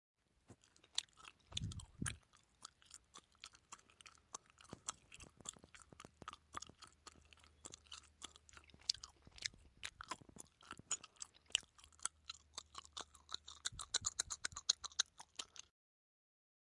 烦人的咀嚼
描述：有人咀嚼烦人的声音
Tag: OWI 咀嚼 用力咀嚼 零食